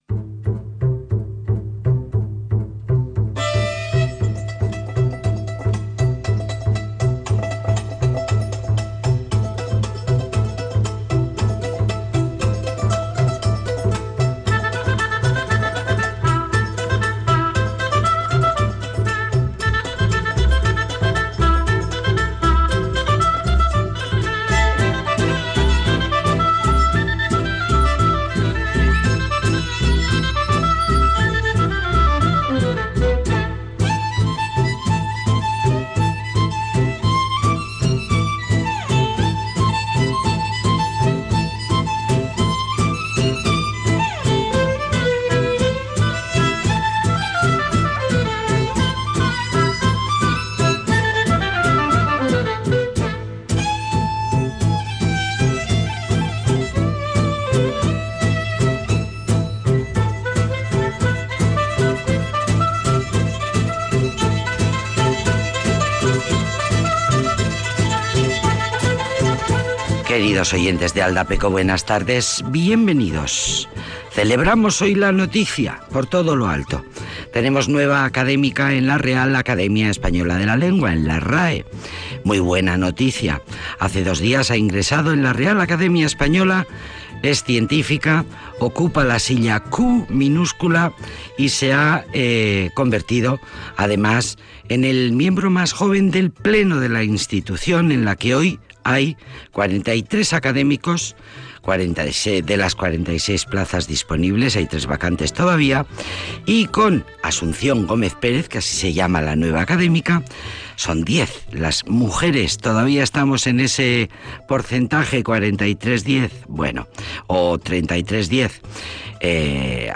Múisca y entrevistas para la sobremesa